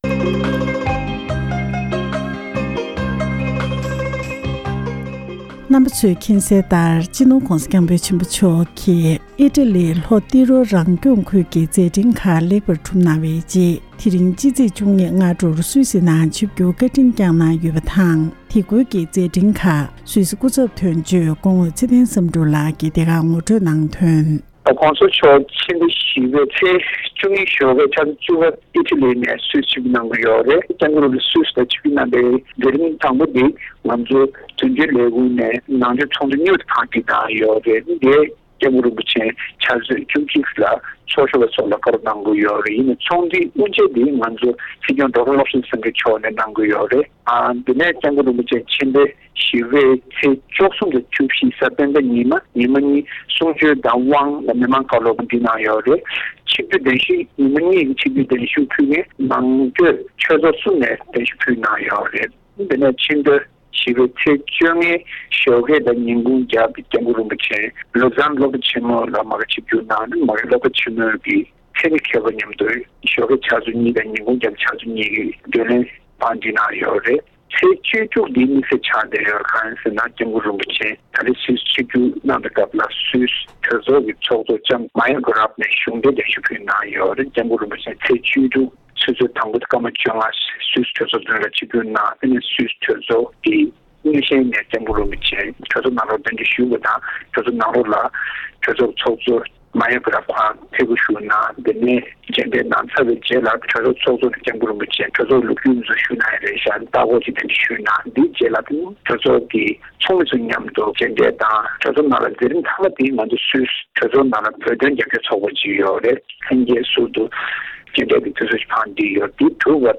འབྲེལ་ཡོད་མི་སྣར་གནས་འདྲི་ཞུས་པར་གསན་རོགས་ཞུ༎